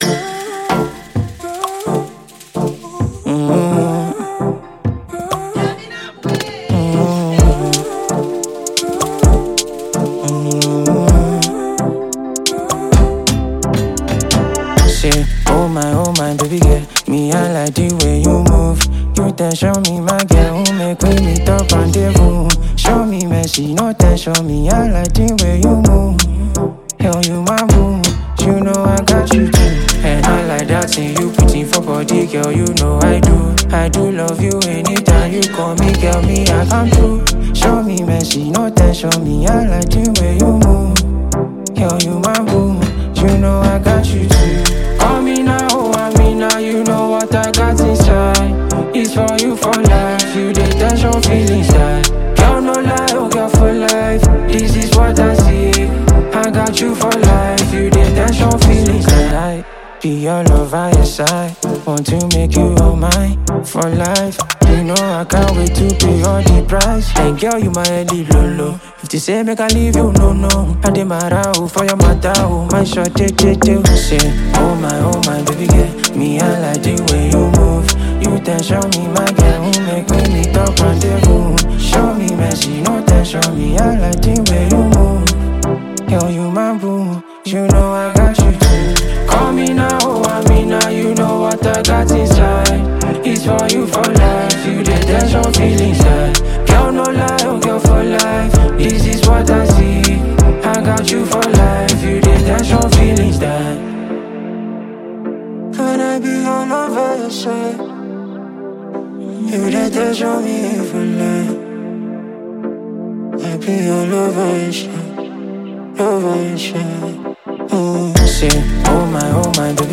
gbedu song